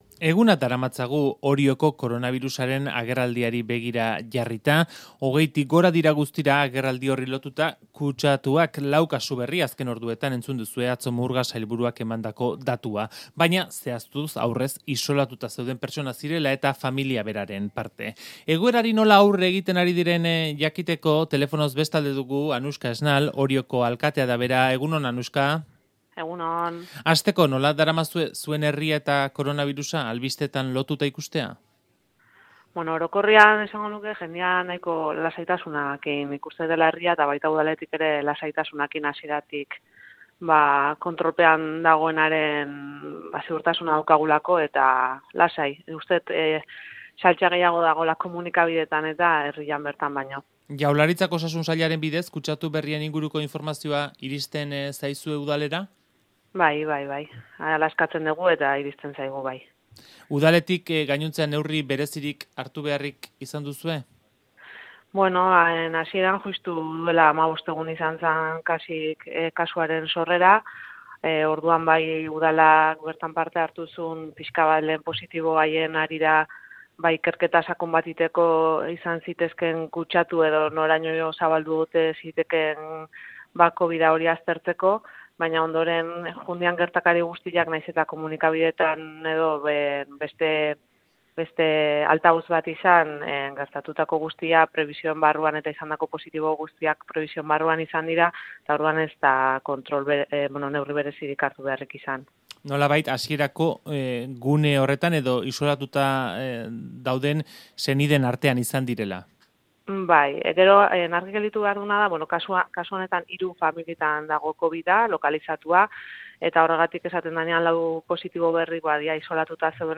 Audioa: Koronabirusaren agerraldia dela eta (23 kasu egun gutxian), lasaitasun mezua helarazi du Euskadi Irratiko Goiz Kronikan Anuska Esnal Orioko alkateak.